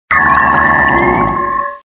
Cri d'Archéodong dans Pokémon Diamant et Perle.